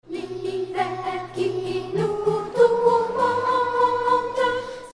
Chant Instrumental balise audio HTML5 inconnue L'hiver qui nous tourmente s'obstine à nous geler.